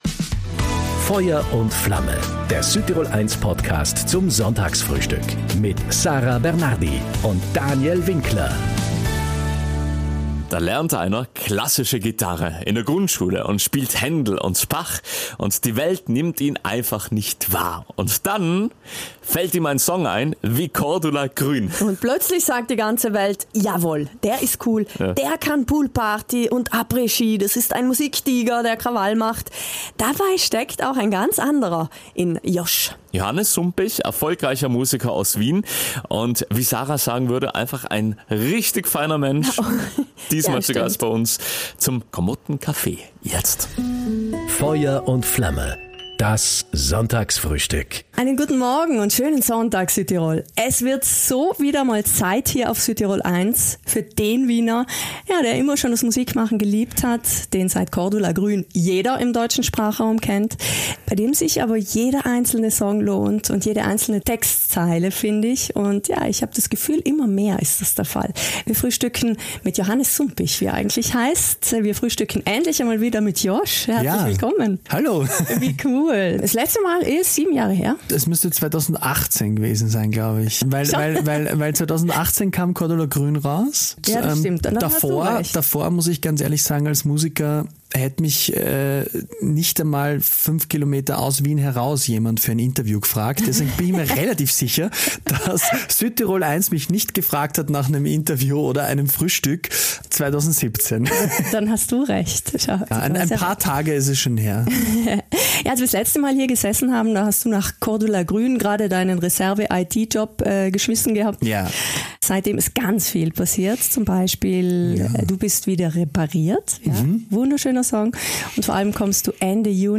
Musiker: Josh